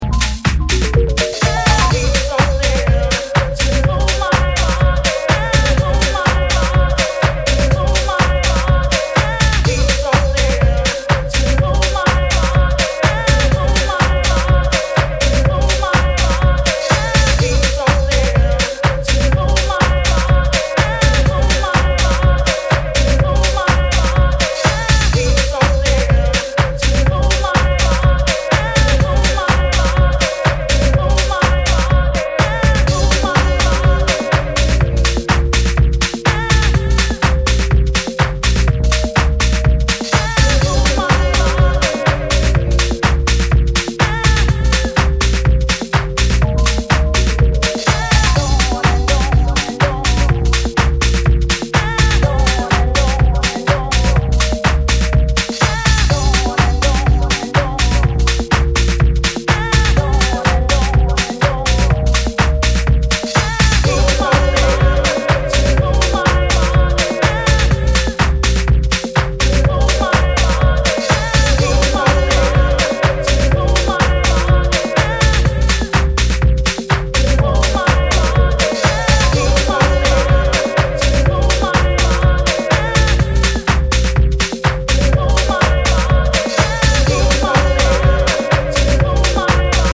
an EP featuring 5 cuts of raw and jacking house music.
soulful and grooving with a dash of garage
timeless dance music: deep, real, and weird